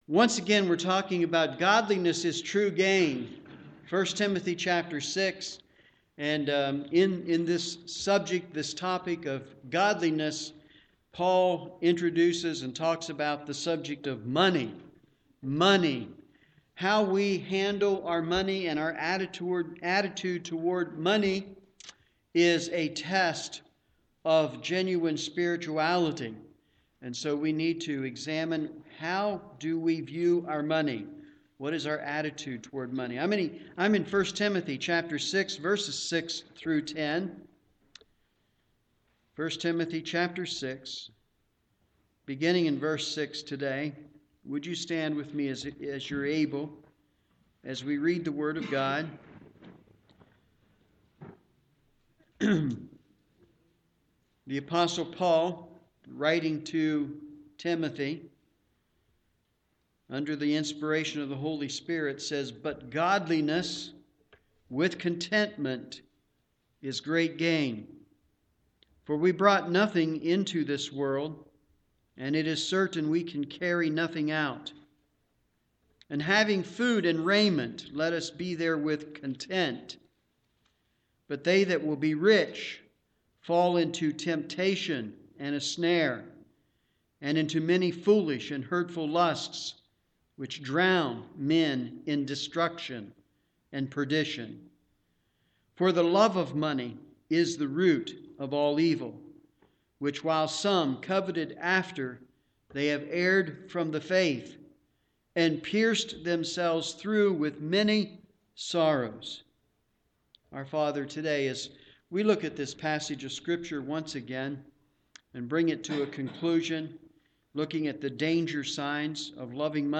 Morning Worship
Sermon